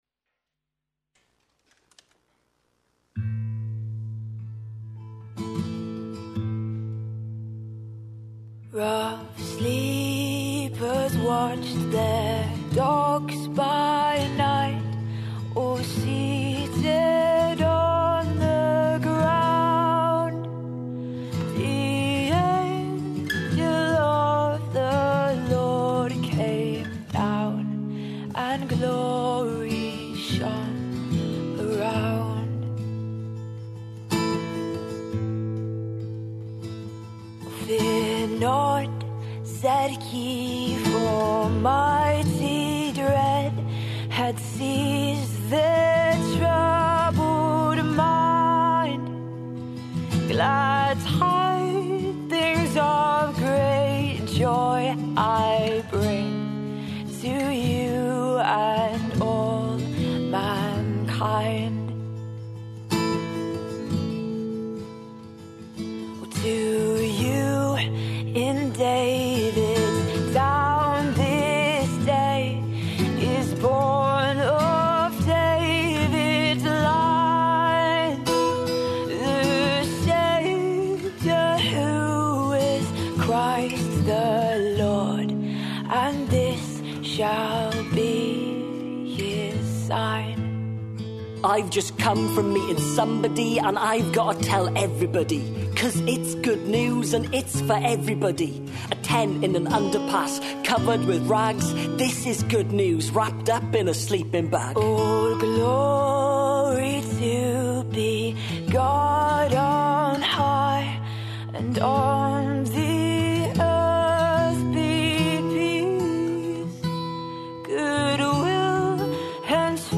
Advent 2021 Watch Listen play pause mute unmute Download MP3 Thanks for joining us for an atmospheric evening of your favourite Christmas Carols in our candlelit church.